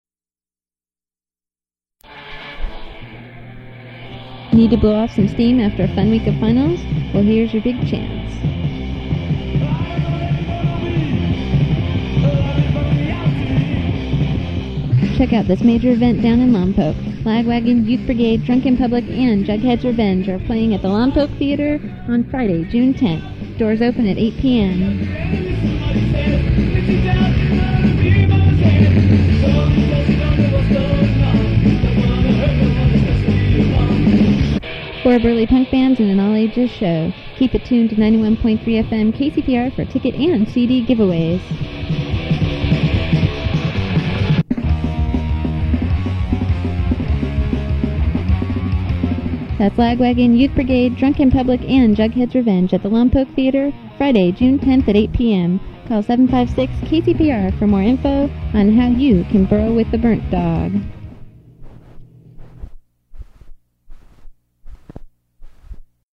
Lagwagon, Youth Brigade, Drunk in Public, Jughead's Revenge at the Lompoc Theater [advertisement]
• Audiocassette